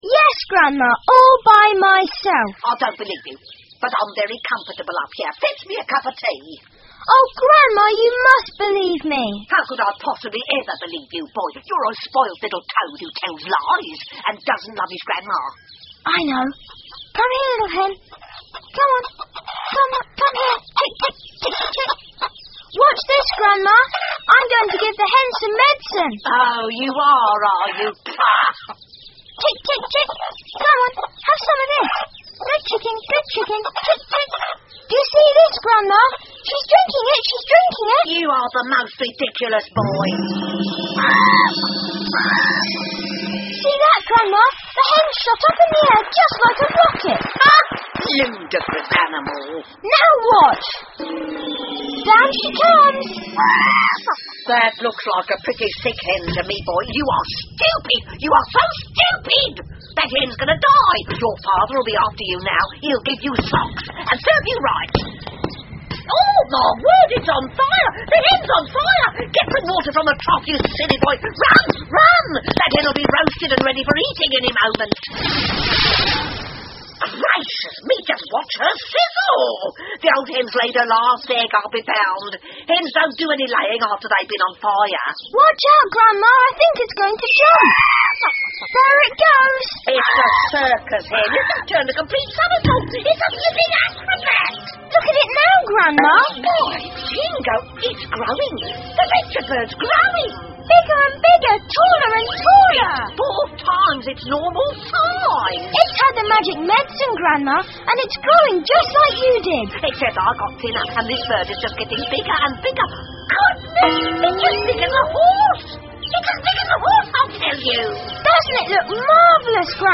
乔治的神奇药水 George's Marvellous Medicine 儿童广播剧 9 听力文件下载—在线英语听力室